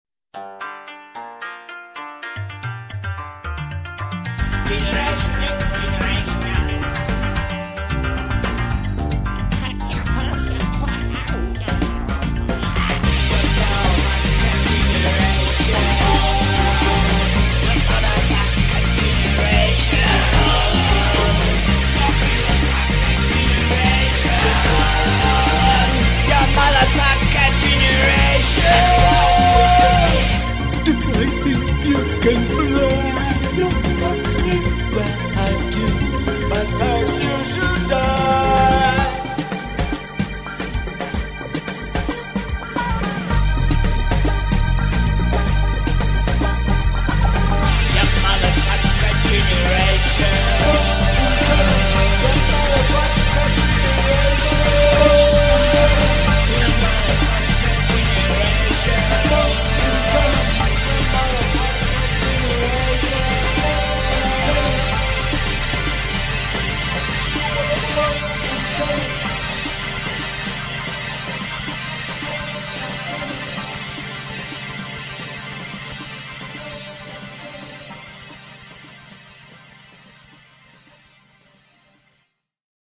вокал и гитара